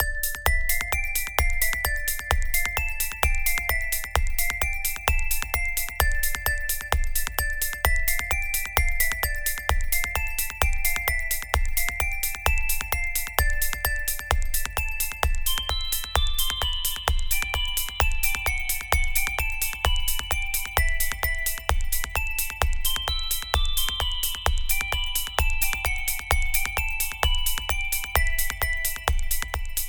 It was a test with the beat and tone samples.